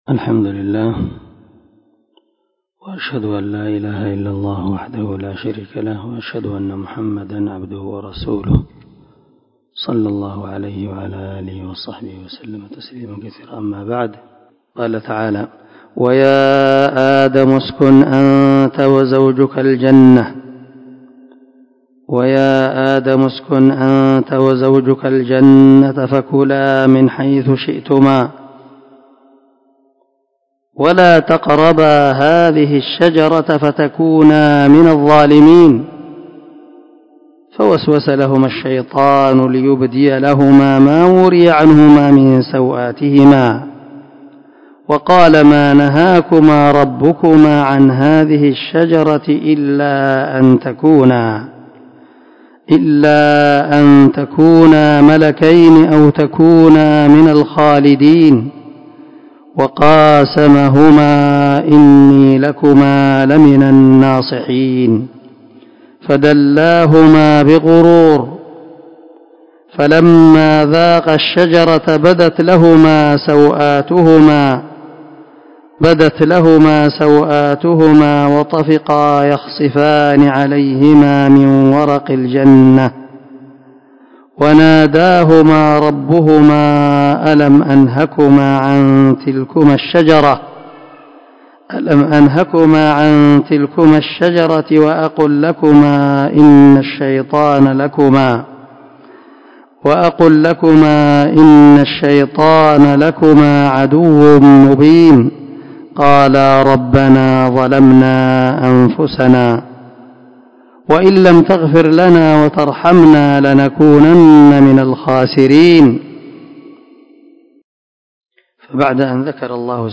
452الدرس 4 تفسير آية ( 19 – 23 ) من سورة الأعراف من تفسير القران الكريم مع قراءة لتفسير السعدي
دار الحديث- المَحاوِلة- الصبيحة.